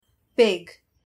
pig.mp3